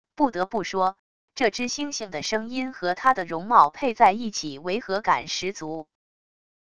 不得不说……这只猩猩的声音和他的容貌配在一起违和感十足wav音频